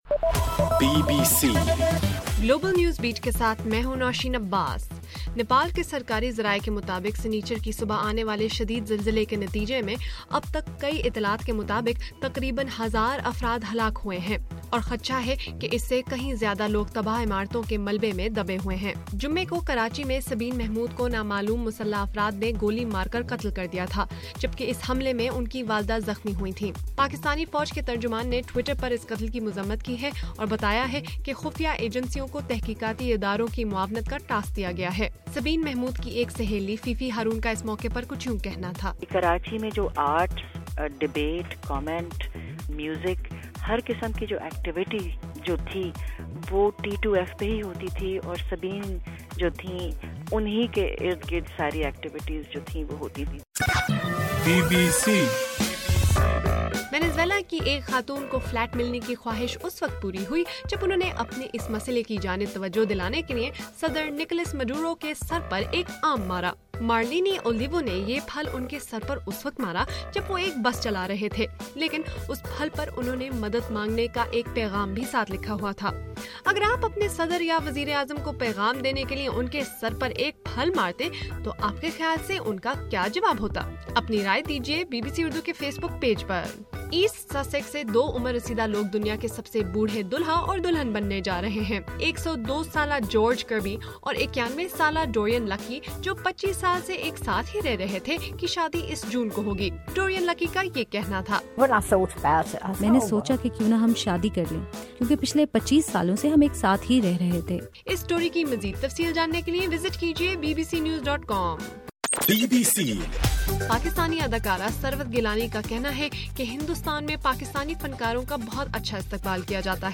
اپریل 25: رات 9 بجے کا گلوبل نیوز بیٹ بُلیٹن